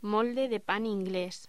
Locución: Molde de pan inglés
voz